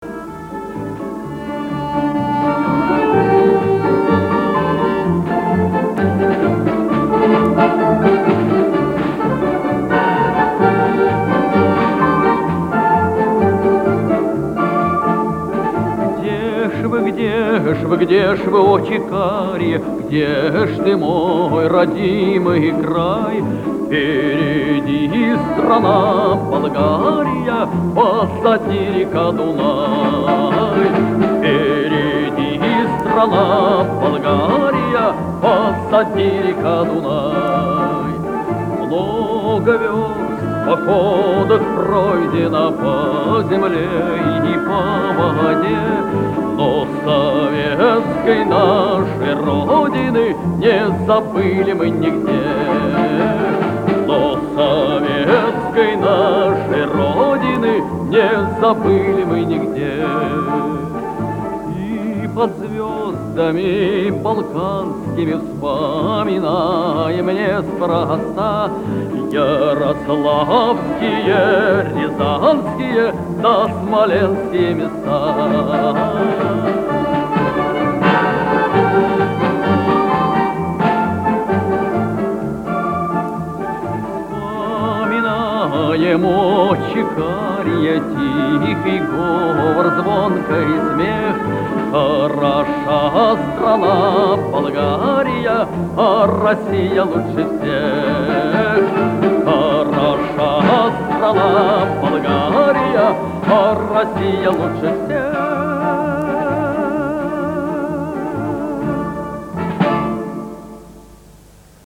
Описание: Ещё один очень мелодичный вариант исполнения